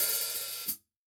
HHHOP-FT.wav